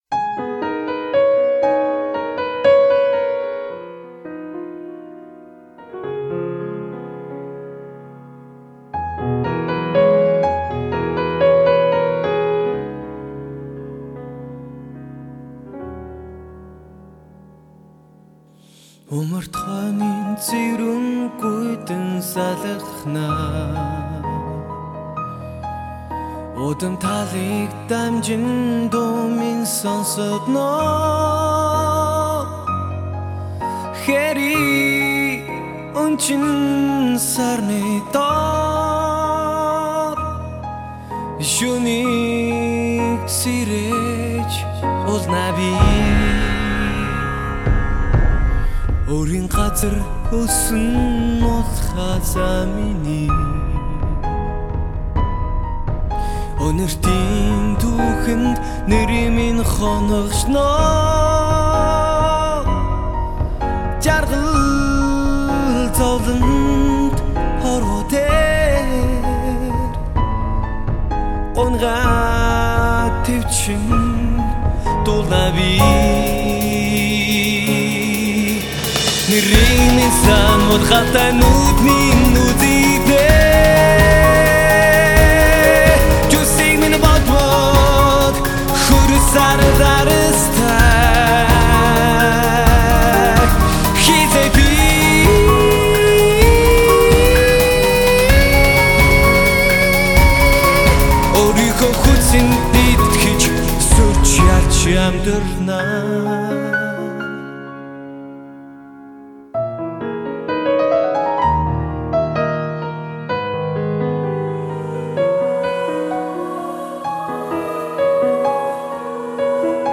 穆式情歌行吟都市草原
守护系男人的真心情歌